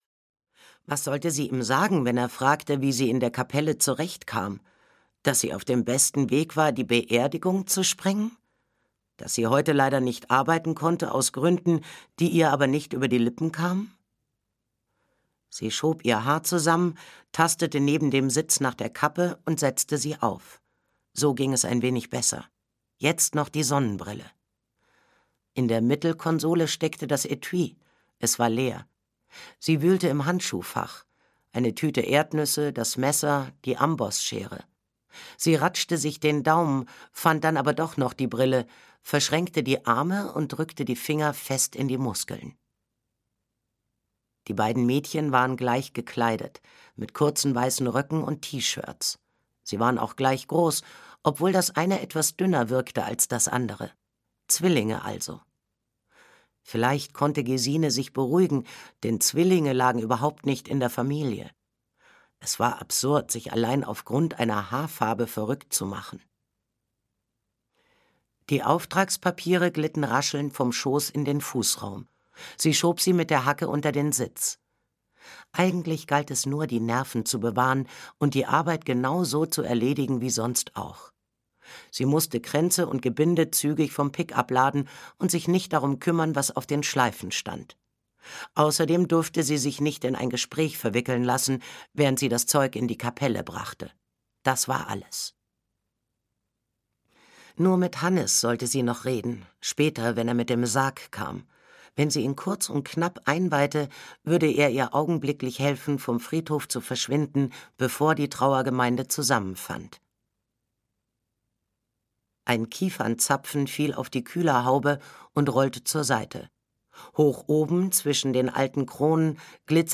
Kaninchenherz - Annette Wieners - Hörbuch